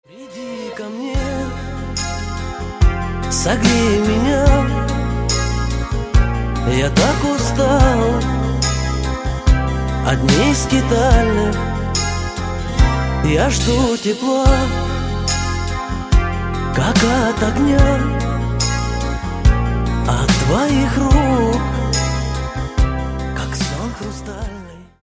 Спокойные И Тихие Рингтоны
Шансон